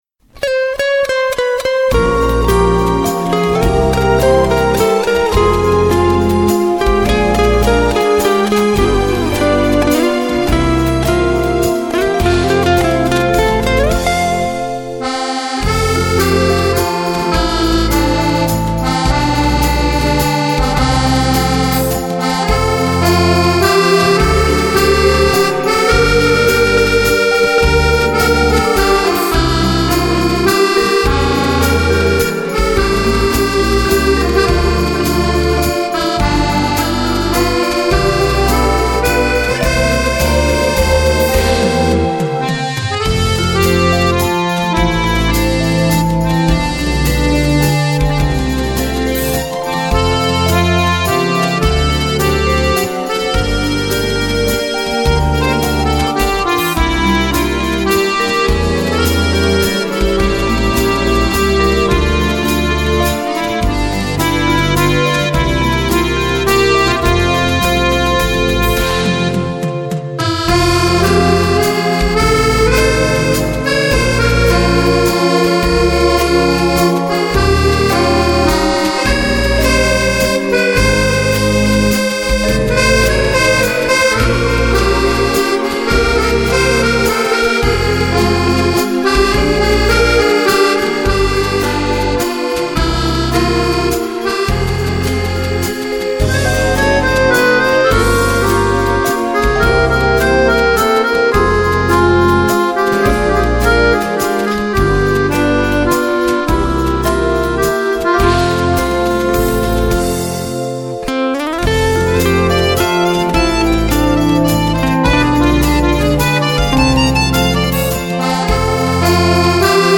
version accordéon